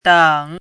“挡”读音
dǎng
挡字注音：ㄉㄤˇ/ㄉㄤˋ
dǎng.mp3